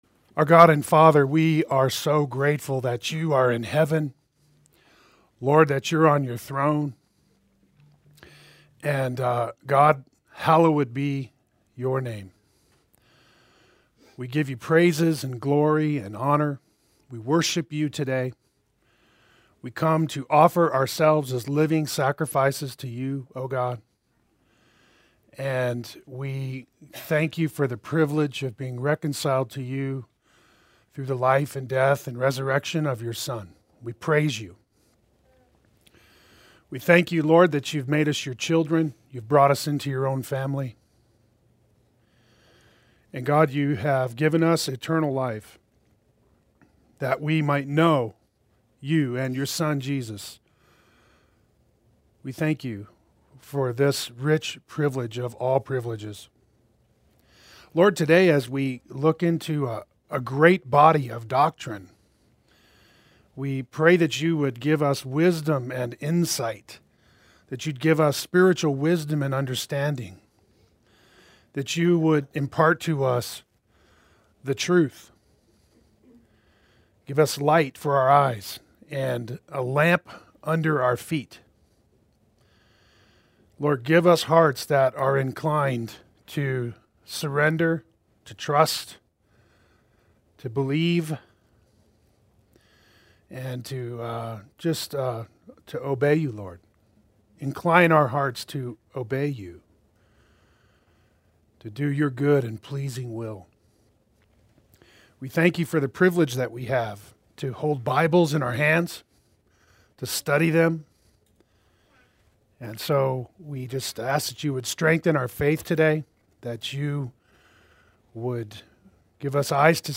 Membership Class #2 Adult Sunday School